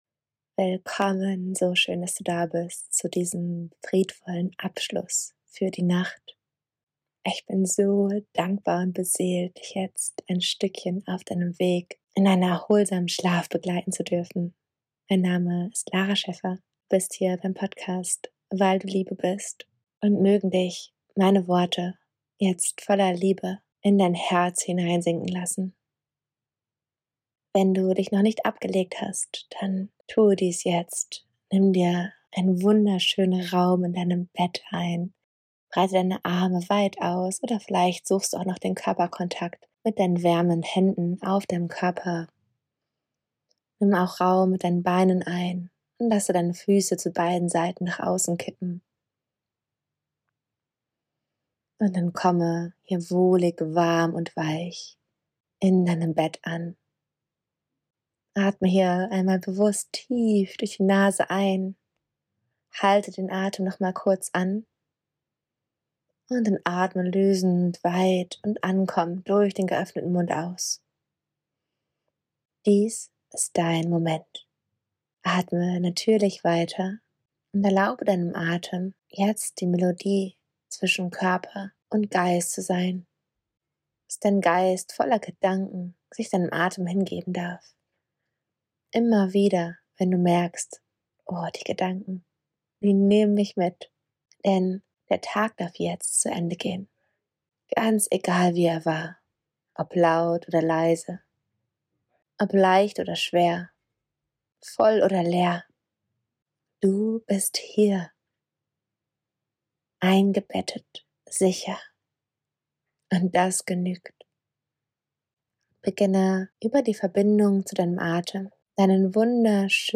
Einschlafmeditation für erholsamen Schlaf
Einschlaf_Reise_fuer_einen_erholsamen_und_friedvollen_Schlaf.mp3